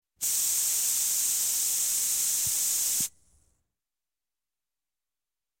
sillystring.ogg